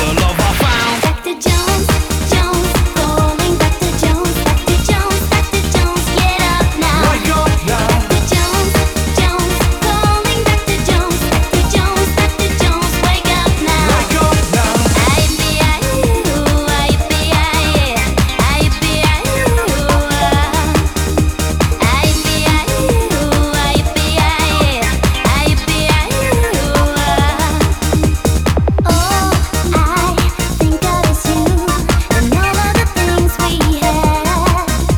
Pop Britpop Dance Electronic
Жанр: Поп музыка / Танцевальные / Электроника